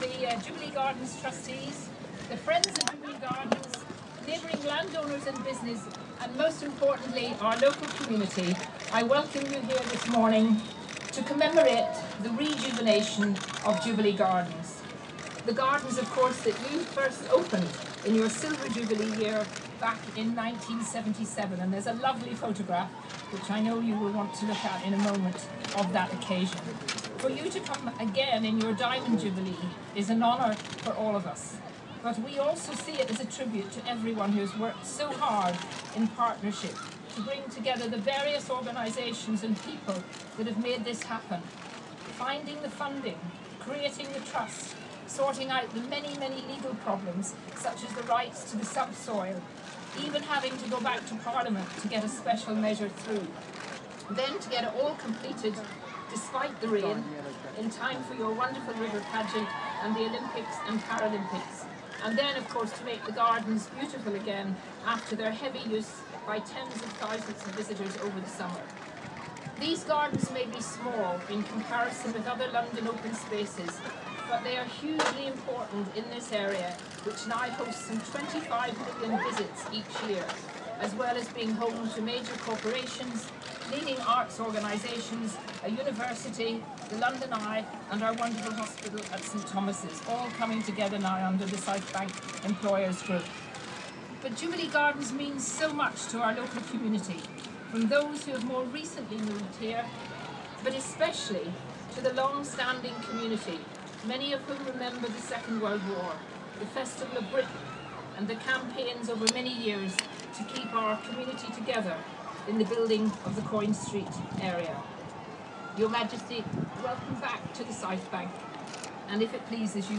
Speech by Kate Hoey during Queen's Jubilee Gardens visit